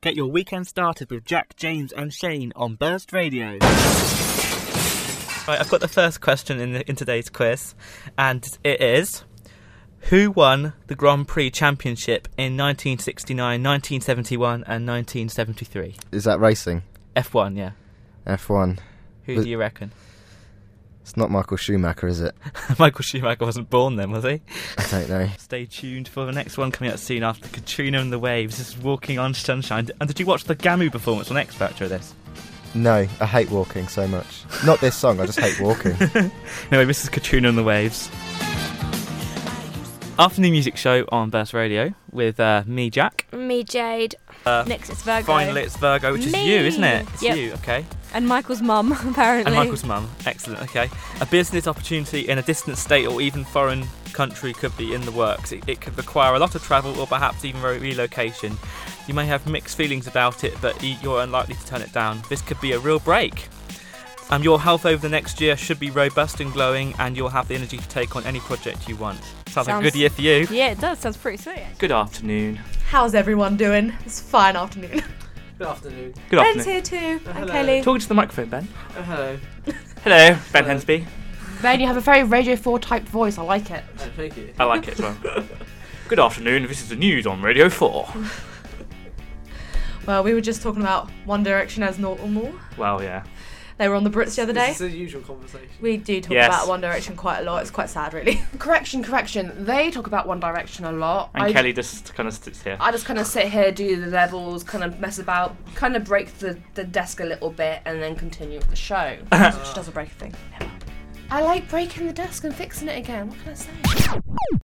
Showreel
A 15-minute radio drama set in Johannesburg, aiming to assist social change relating to HIV